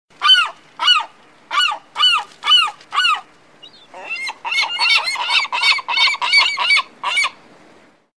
sea-gulls.mp3